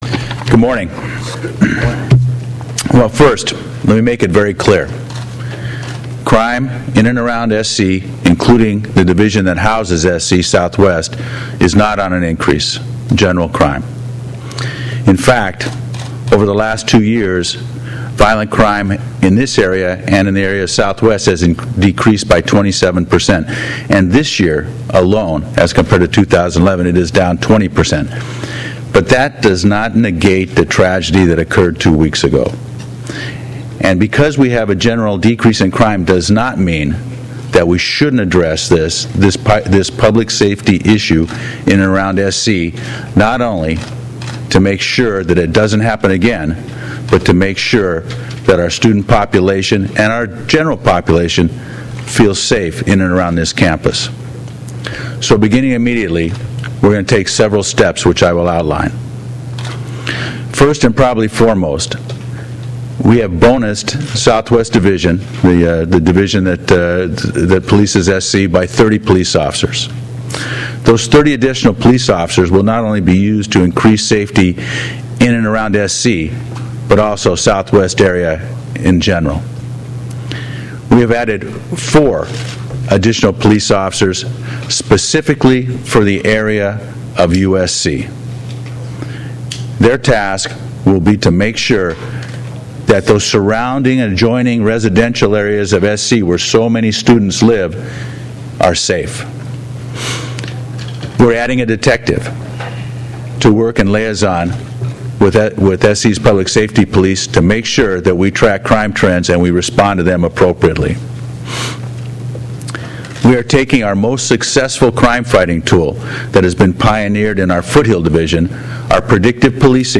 USC News Conference
Thursday April 26, 2025 - In a news conference held on the campus of the University of Southern California (USC), Police Chief Charlie Beck along with Mayor Antonio Villaraigosa and USC President C. L. Max Nikias joined together to address some of the safety concerns students and the public have in the wake of the recent shooting involving two USC graduate students.  Chief Beck outlined some of the changes he made in deployment to address those concerns.